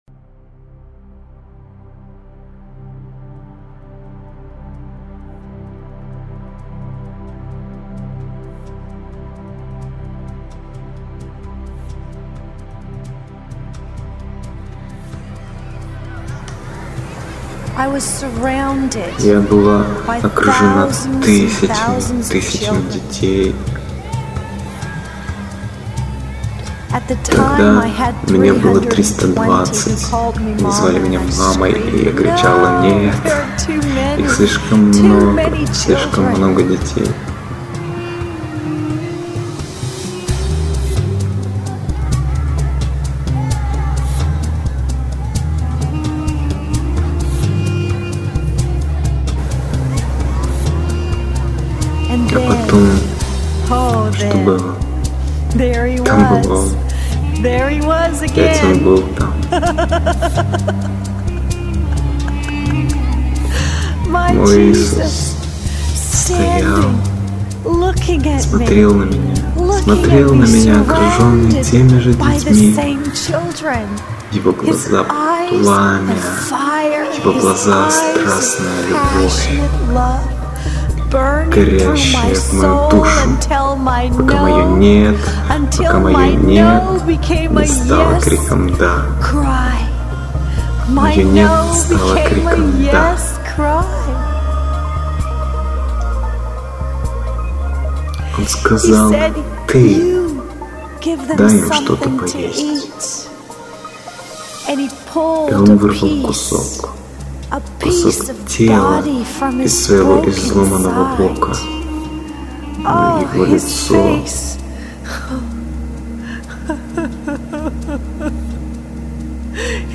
Альбом переведен на русский язык  на фоне прекрасной,  помазанной музыки.